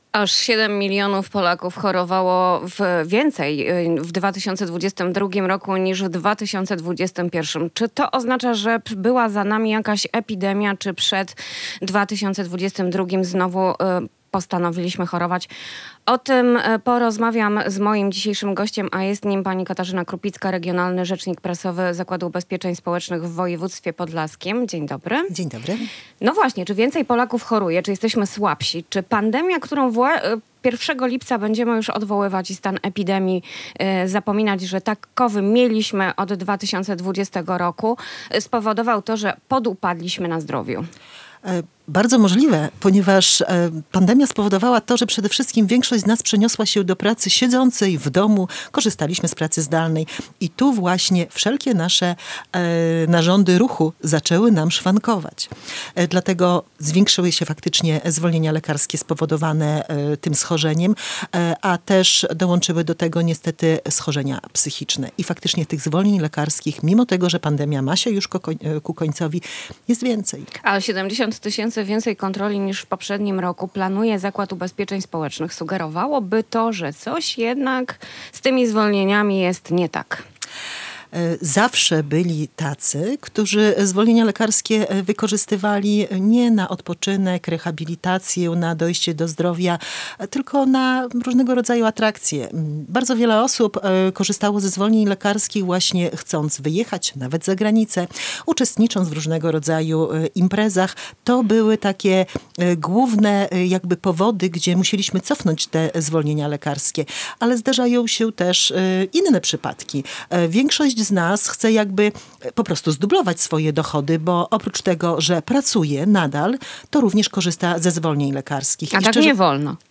Gość